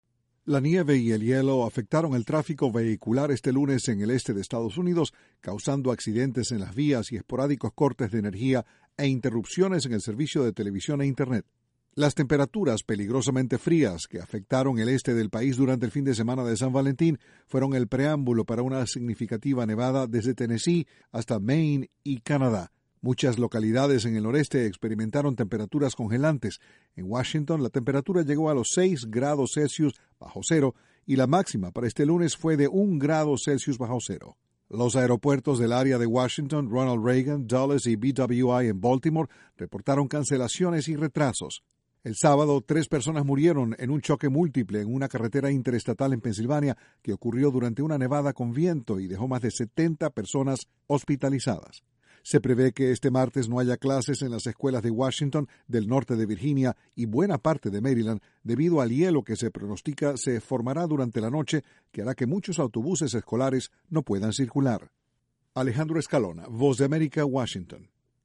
Una tormenta de nieve, aunque de menor intensidad que la de hace unas semanas, paralizó el este de Estados Unidos. Desde la Voz de América, Washington, informa